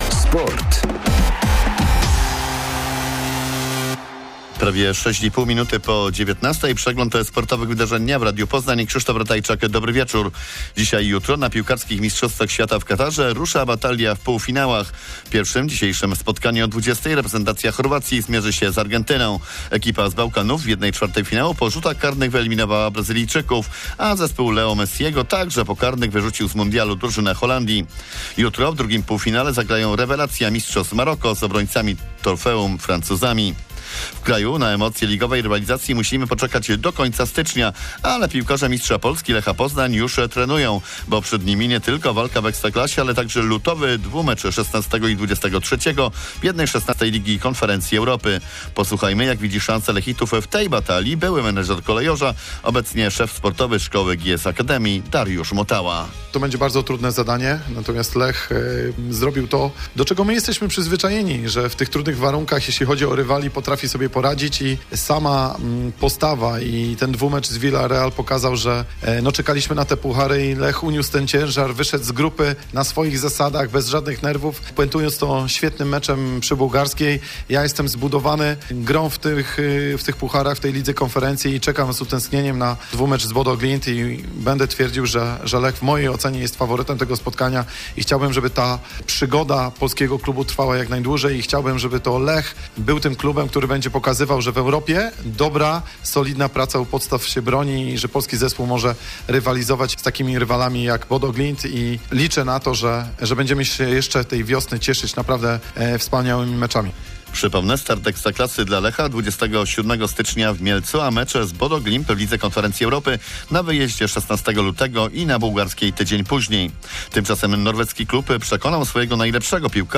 13.12.2022 SERWIS SPORTOWY GODZ. 19:05